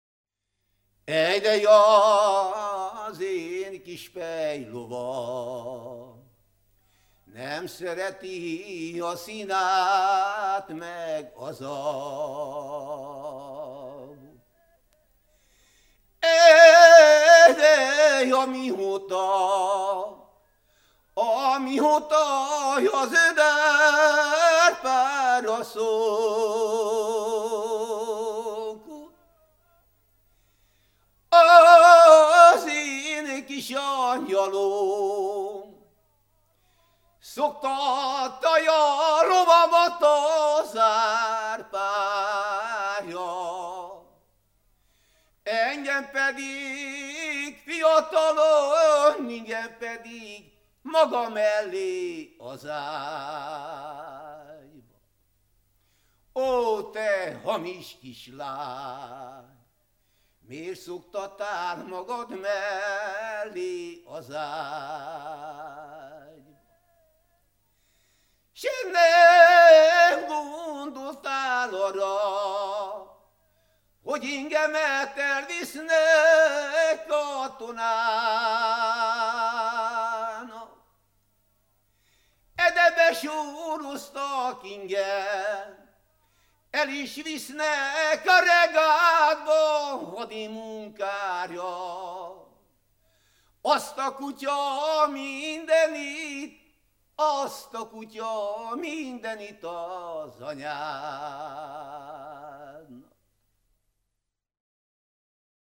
ének
Nádas mente (Kalotaszeg, Erdély)